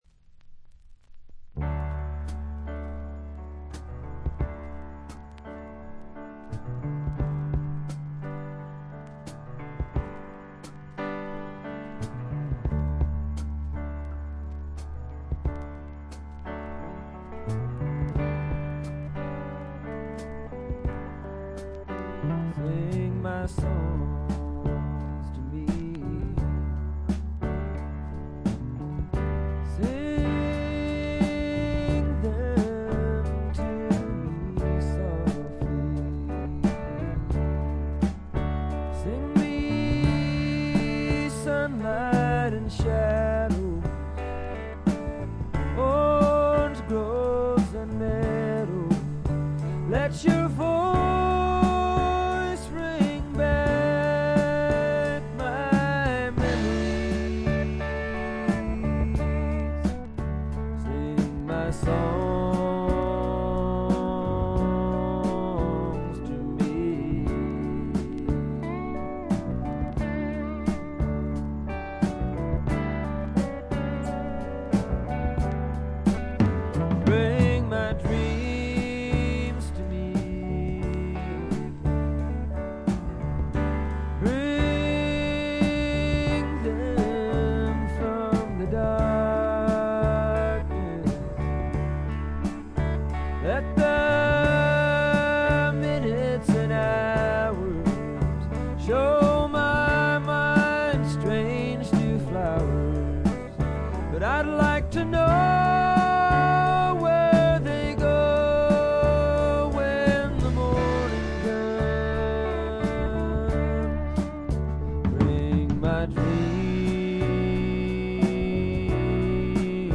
シンガー・ソングライター物としては完璧な1枚ではないでしょうか。